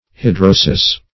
Hidrosis \Hi*dro"sis\, n. [Written also, but incorrectly,